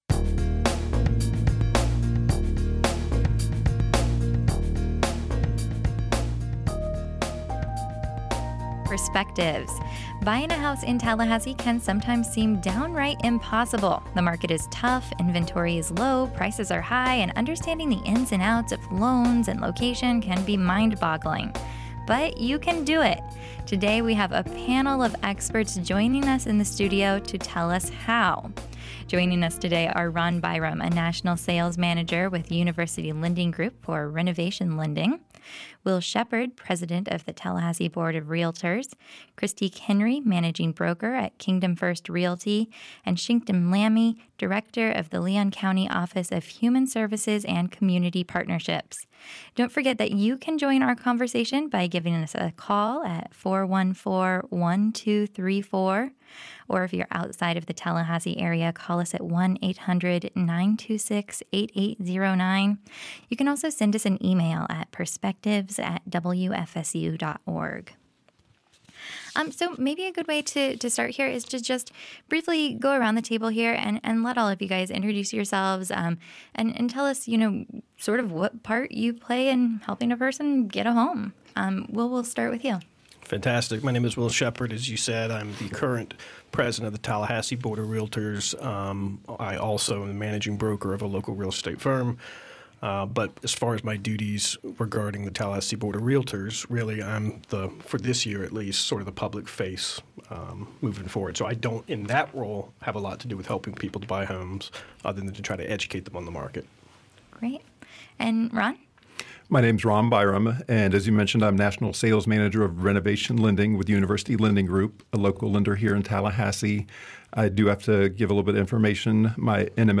Joining our conversation in studio are: